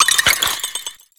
Cri de Sorboul dans Pokémon X et Y.